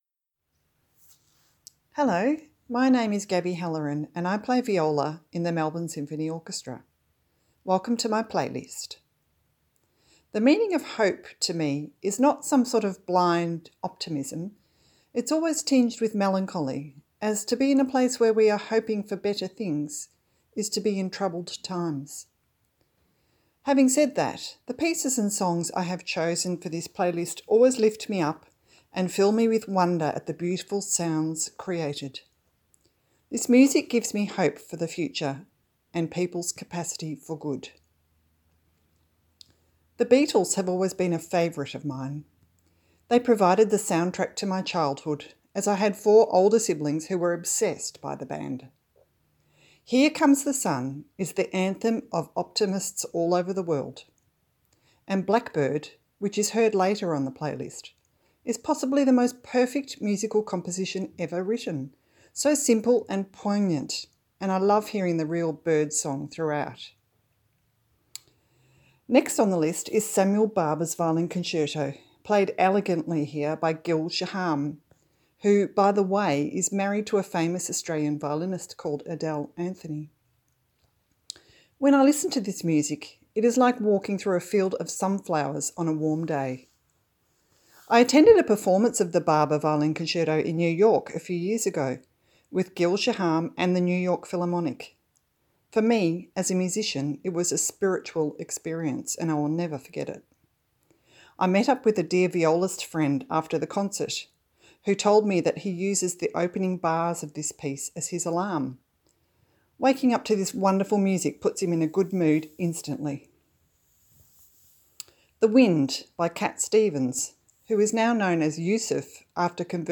Each musician has provided a short introduction to their playlist exploring the meaning the music has for them personally.